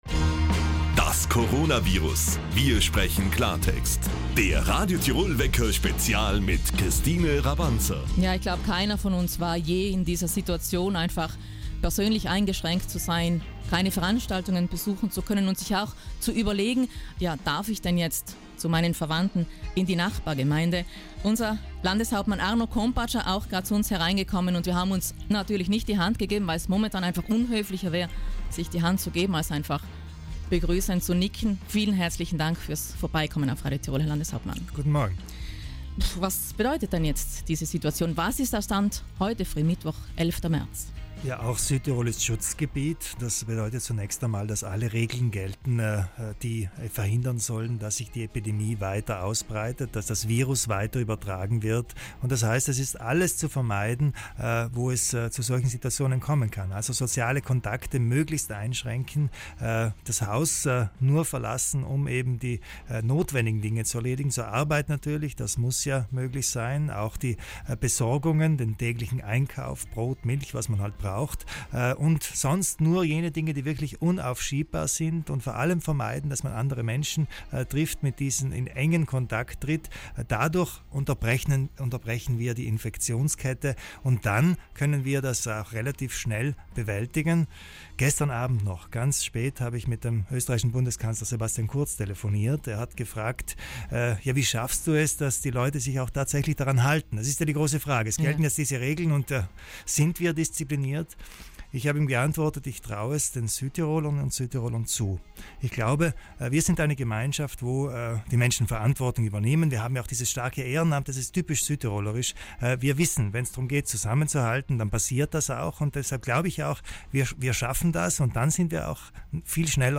Landeshauptmann Arno Kompatscher hat Ihre Fragen zur Corona-Notverordnung im Radio Tirol Wecker